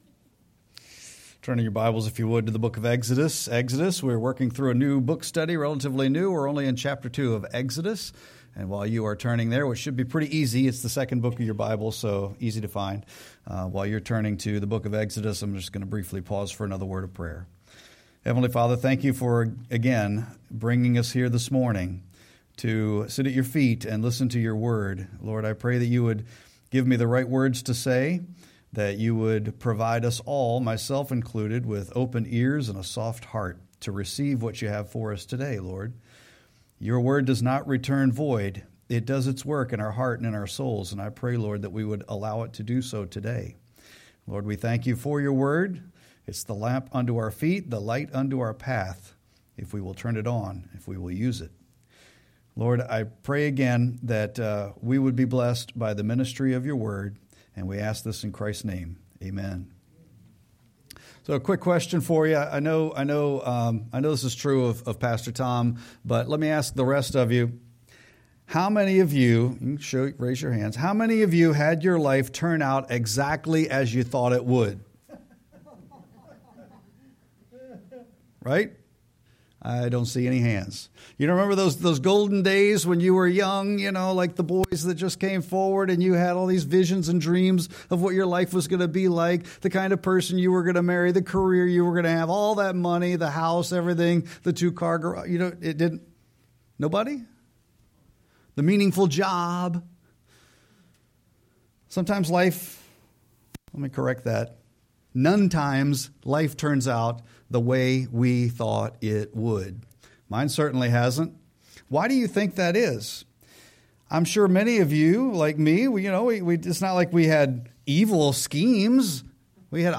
Sermon-1-18-26.mp3